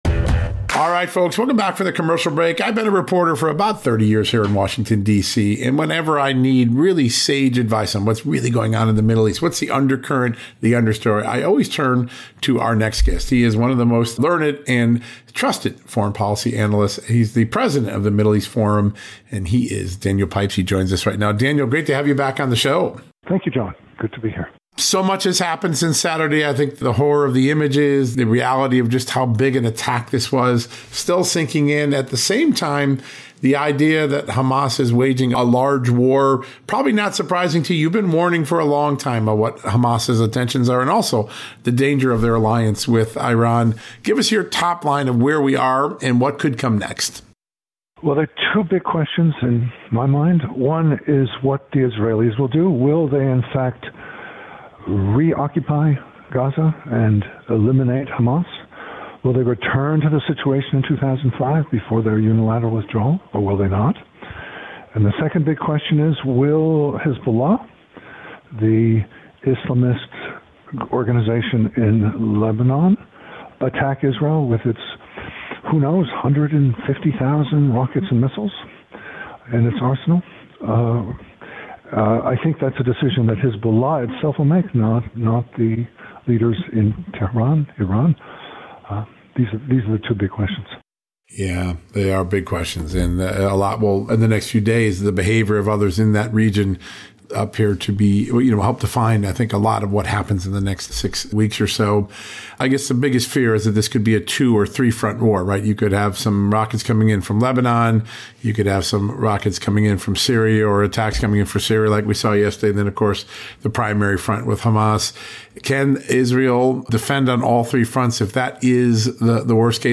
Interviews with Daniel Pipes